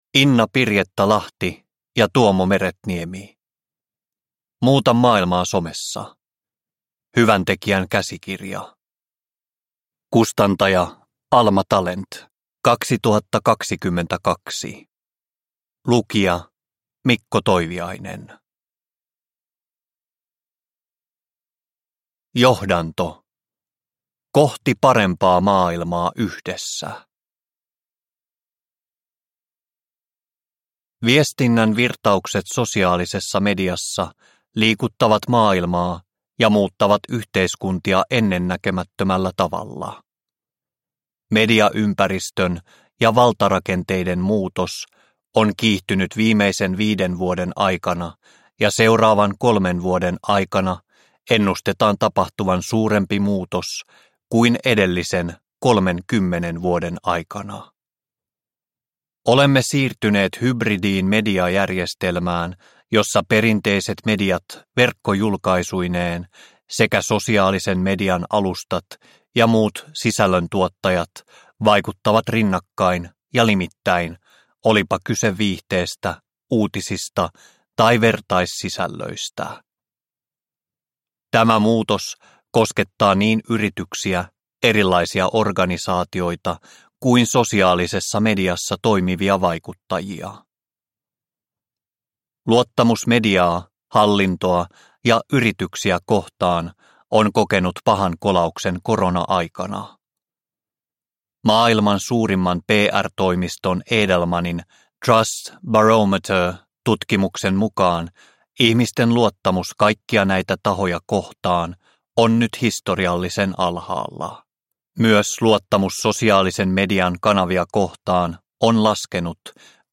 Muuta maailmaa somessa – Ljudbok – Laddas ner